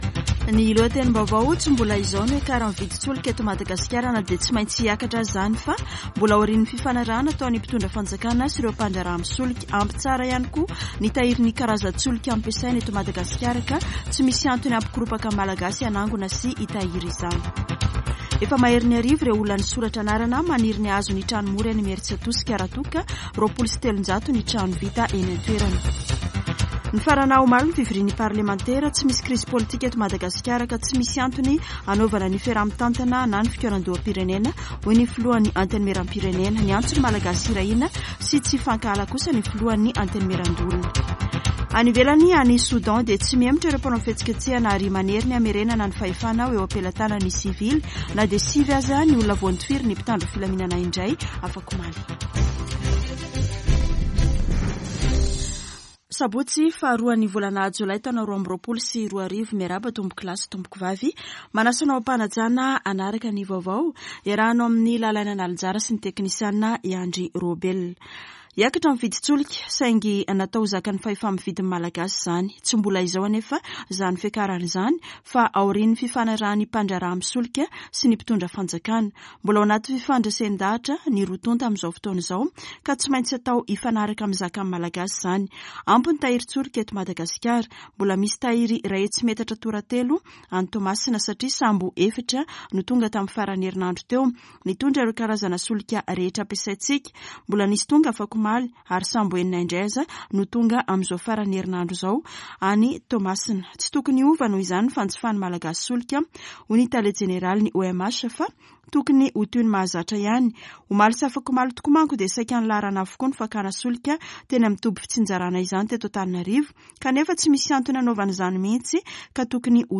[Vaovao maraina] Sabotsy 02 jolay 2022